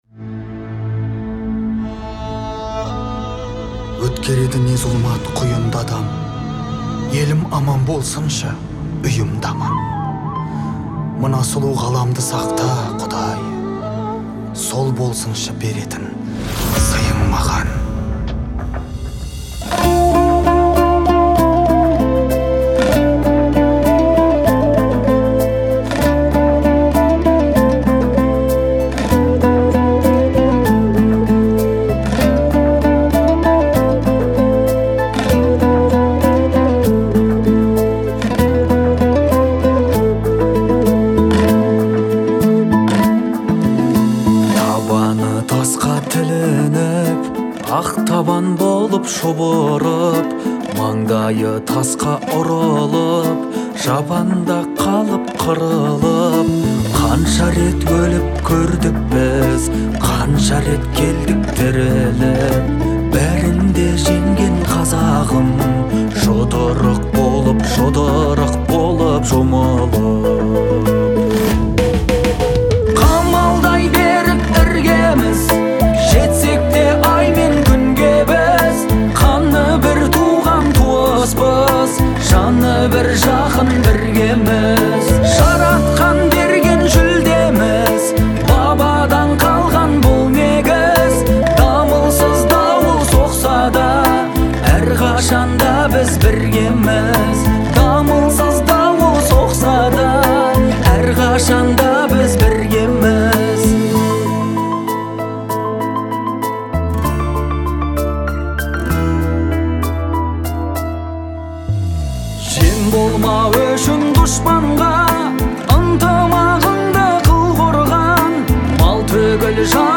это трек в жанре казахской поп-музыки